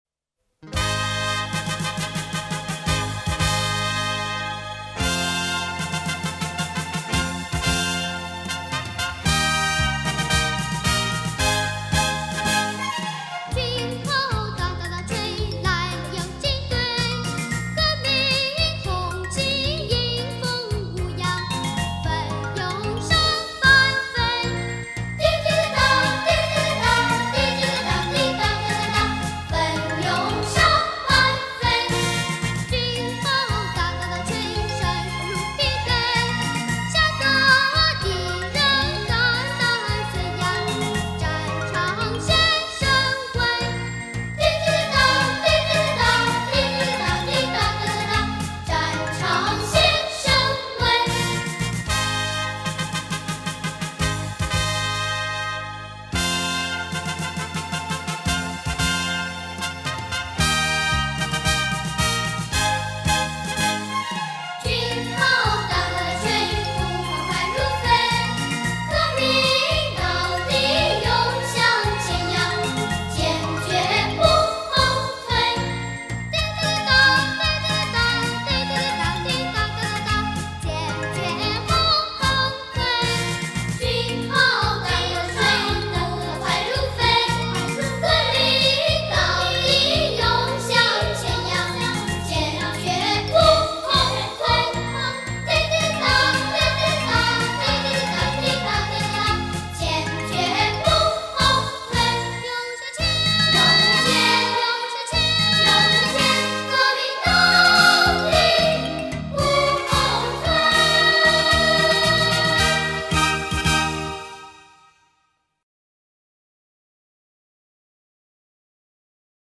主题曲